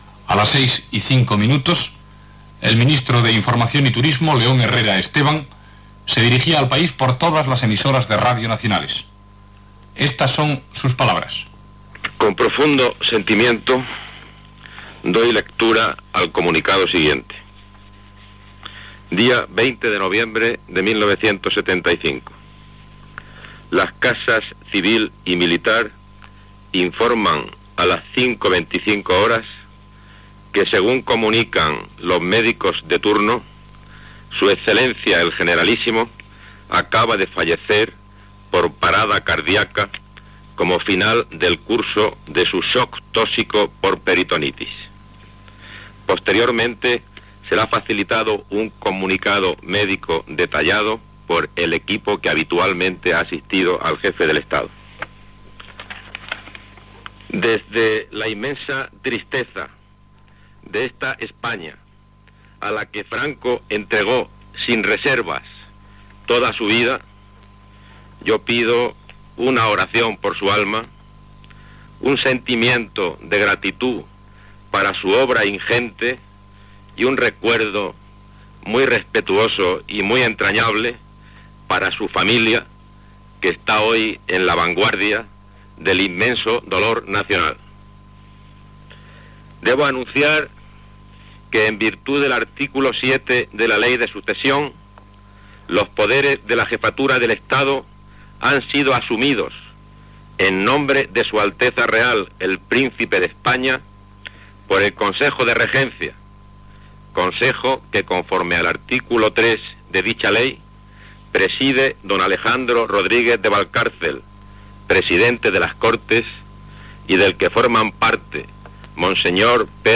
3915ff2949b80644a456bcf2875e85419d36f3af.mp3 Títol Radio Nacional de España Emissora Radio Nacional de España Barcelona Cadena RNE Titularitat Pública estatal Nom programa España a las 7 Descripció Informació especial a les 7 hores del matí. Paraules del ministre d'Información y Turismo, León Herrera, sobre la mort del "Generalísimo" Francisco Franco a les 5:25 hores de la matinada.
Gènere radiofònic Informatiu